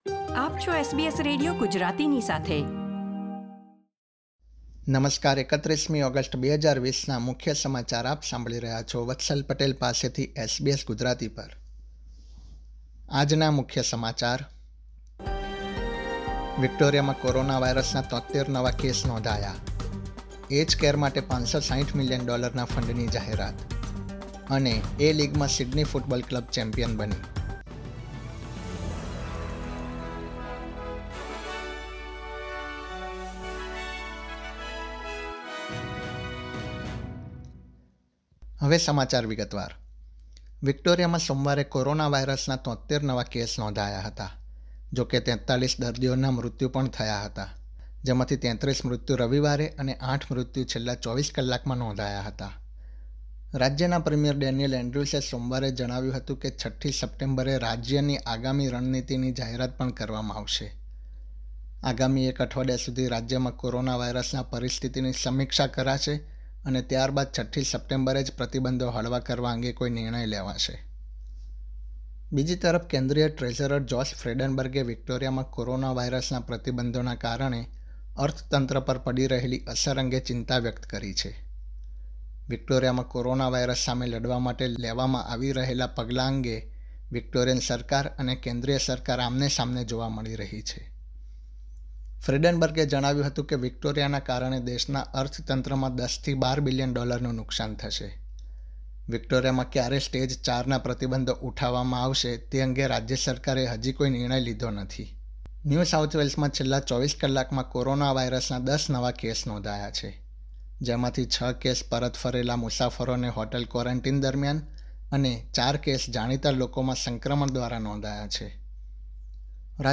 SBS Gujarati News Bulletin 31 August 2020
gujarati_3108_newsbulletin.mp3